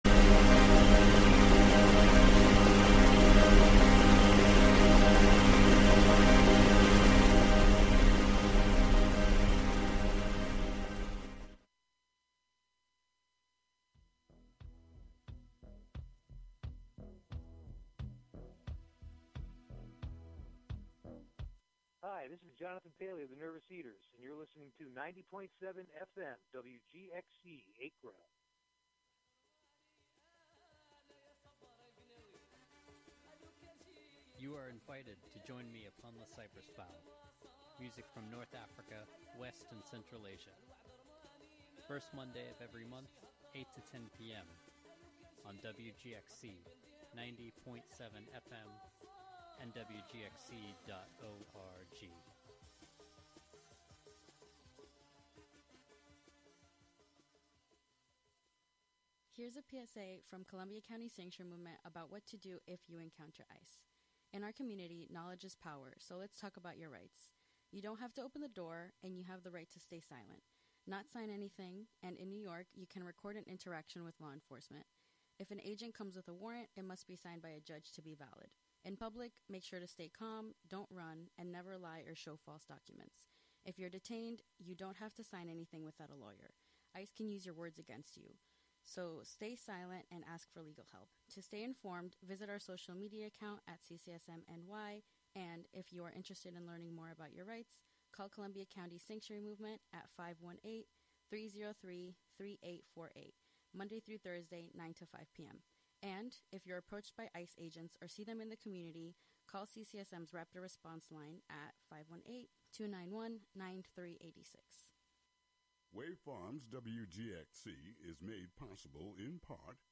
We have a special sonic extension of their pickling practice in this show. We also discuss the unique qualities of their fermentation work, how Ayurvedic principles intersect with their ferments and operations, and the possibility of forming relation and awakening a kind of somatic knowing by engaging in fermentation.